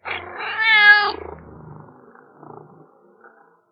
PixelPerfectionCE/assets/minecraft/sounds/mob/cat/purreow1.ogg at mc116
purreow1.ogg